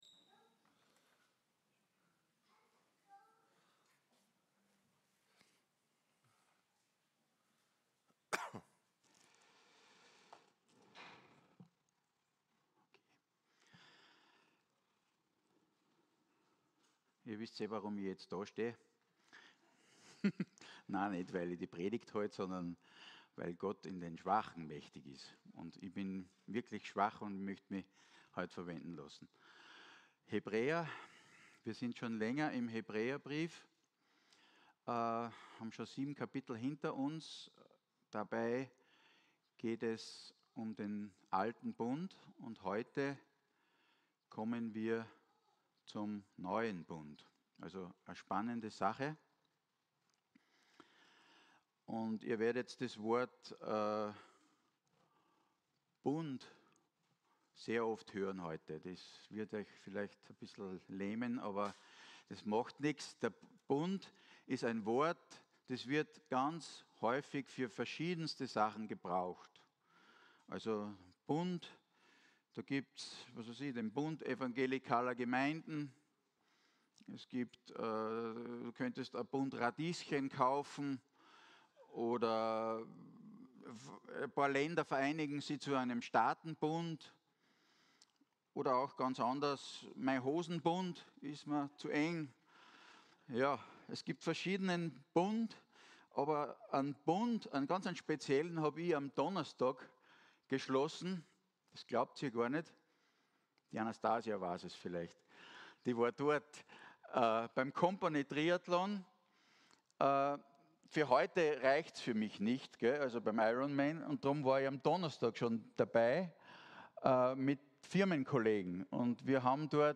Aktuelle Predigtreihe – FEG Klagenfurt